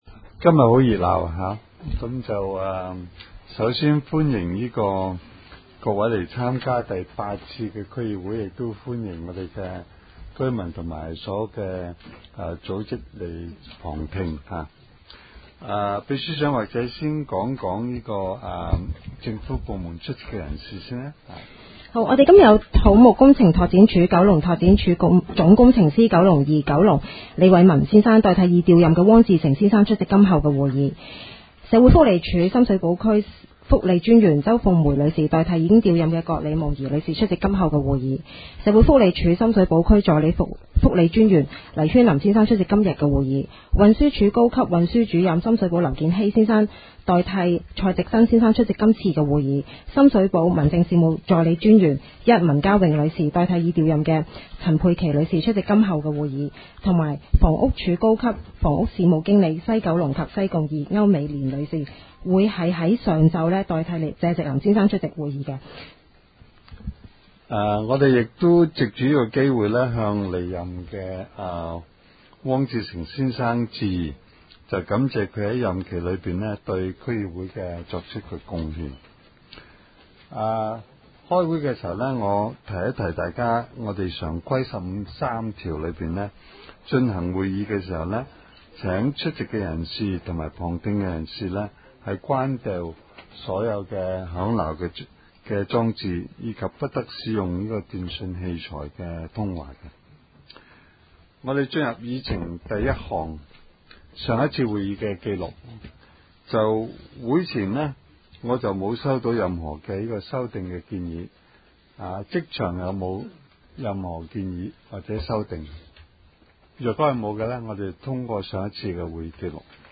区议会大会的录音记录
深水埗区议会第八次会议
深水埗区议会会议室